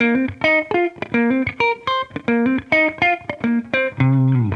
Index of /musicradar/sampled-funk-soul-samples/105bpm/Guitar
SSF_TeleGuitarProc1_105C.wav